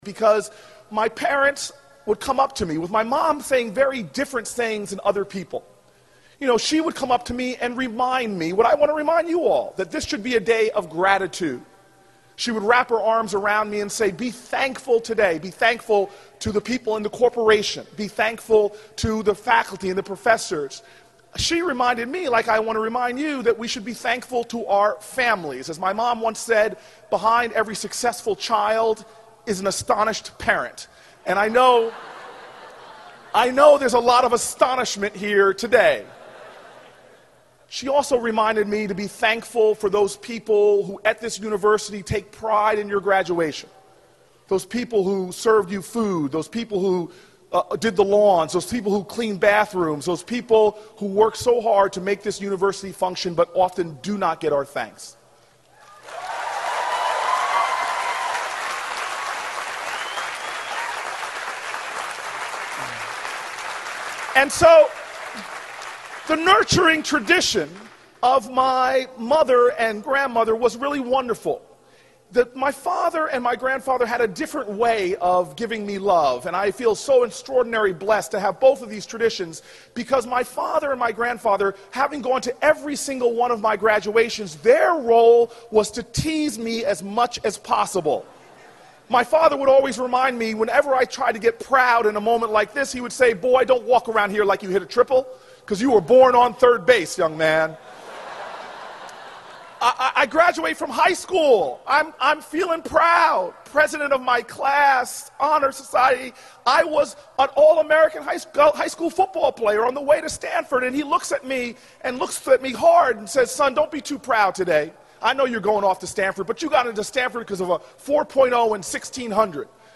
公众人物毕业演讲第437期:科里布克2013年耶鲁大学(5) 听力文件下载—在线英语听力室